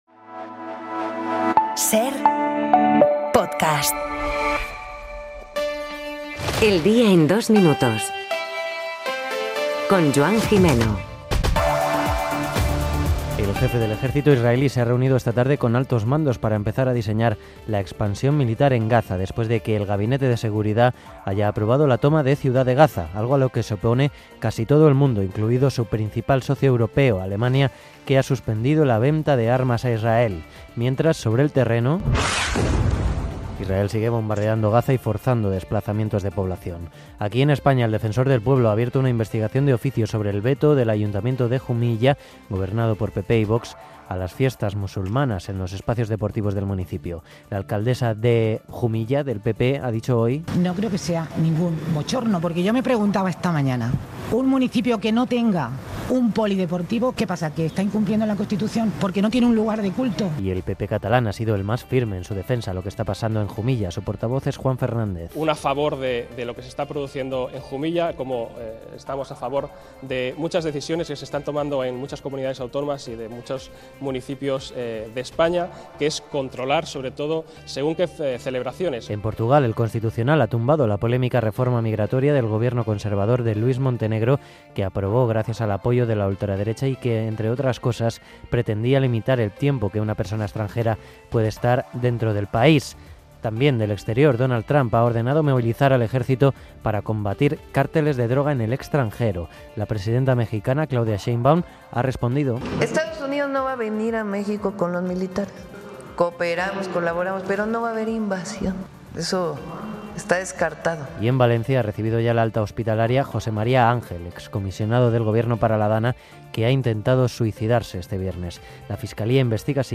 Un resumen de las noticias de hoy